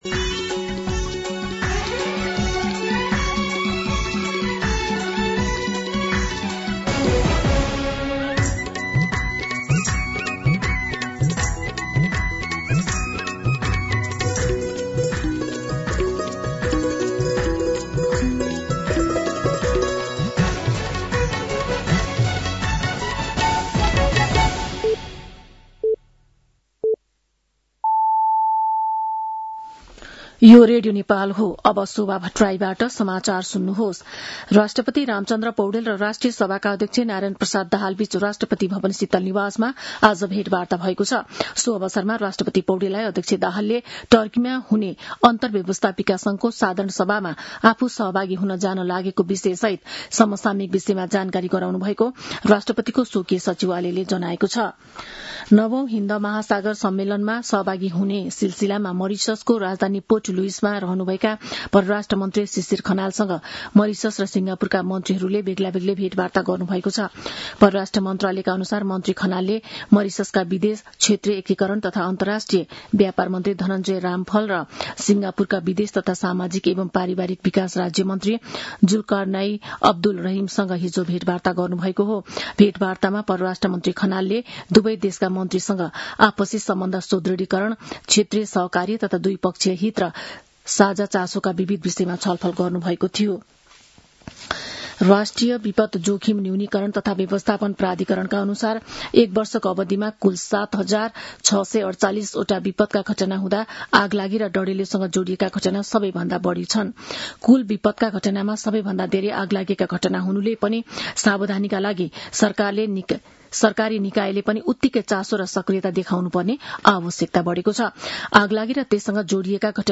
An online outlet of Nepal's national radio broadcaster
मध्यान्ह १२ बजेको नेपाली समाचार : २९ चैत , २०८२